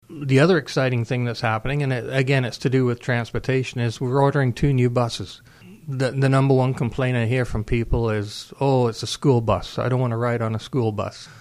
Councillor Macintosh also explains another transit improvement: